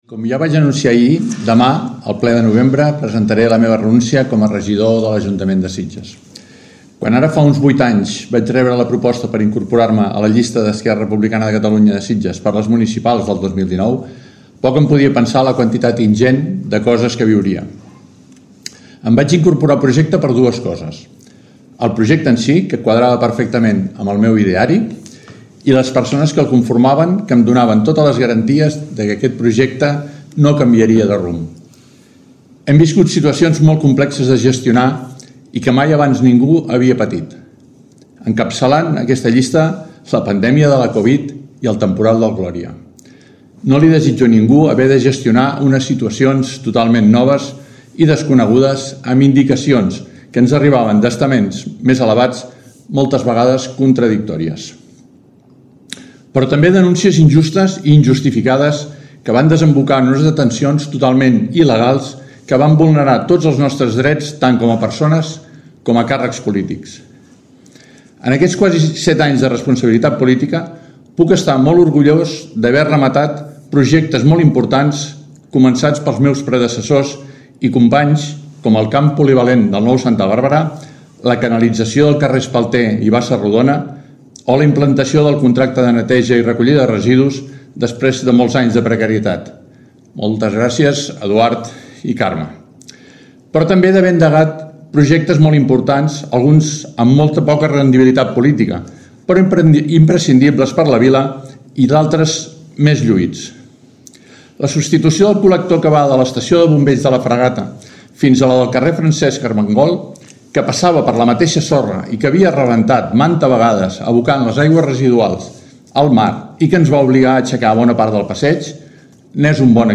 Després de prop de set anys com a regidor Jaume Monasterio ha comparegut aquest matí per explicar els motius pels quals ha decidit deixar la primera línia política i que passen per una tria personal acompanyada de cert esgotament per situacions viscudes que considera injustes i injustificades.